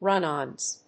/ˈrʌˈnɑnz(米国英語), ˈrʌˈnɑ:nz(英国英語)/